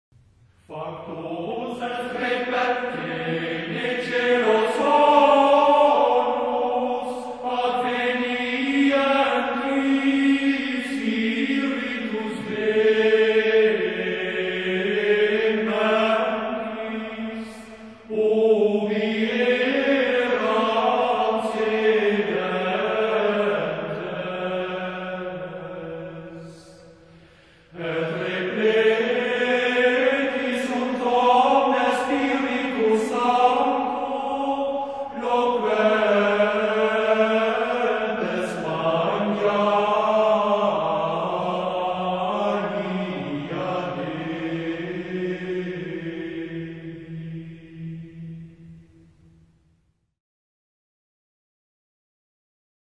Очень профессионально исполнено.